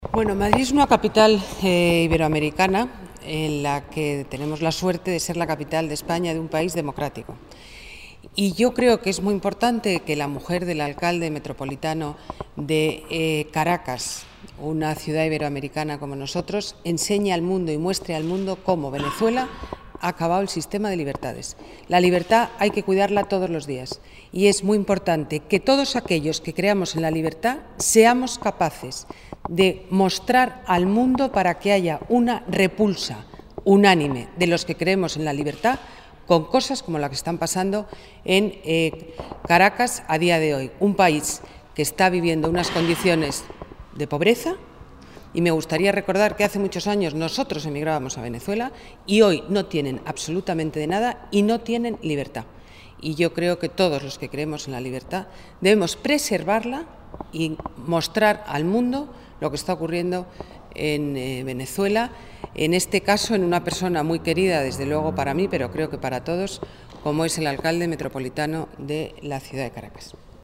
Nueva ventana:Declaraciones alcaldesa Madrid, Ana Botella: reunión esposa alcalde Caracas, repulsa encarcelamiento